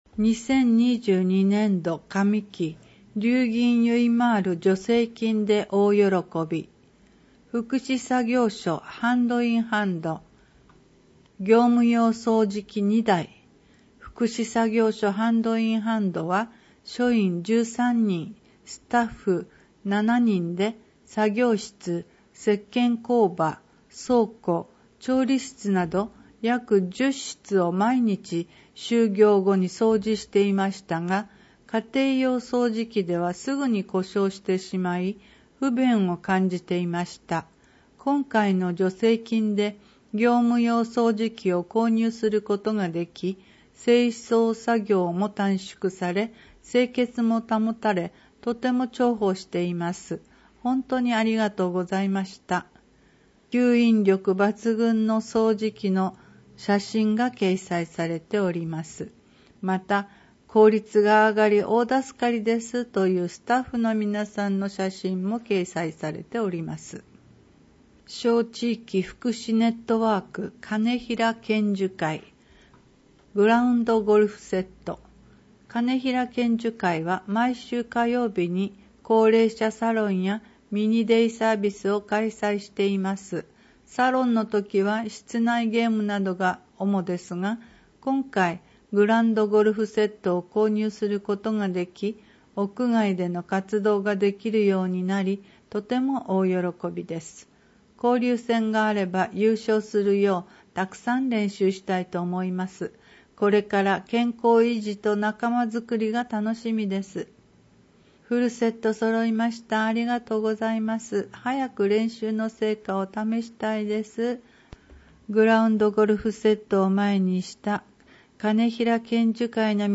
音訳ファイル